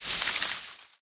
dc0f4c9042 Divergent / mods / Soundscape Overhaul / gamedata / sounds / material / human / step / bush01gr.ogg 12 KiB (Stored with Git LFS) Raw History
bush01gr.ogg